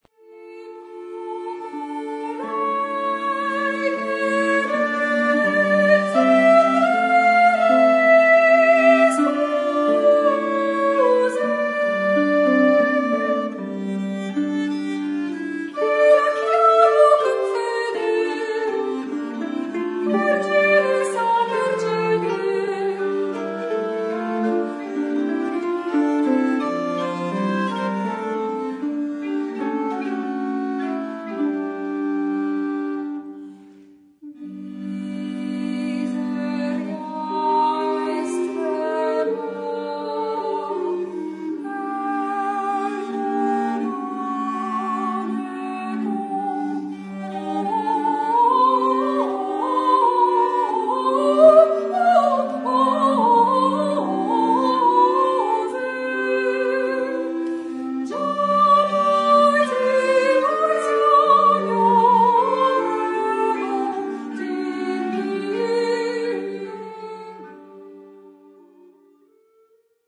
vocals
viola da gamba
renaissance harp
organetto
flute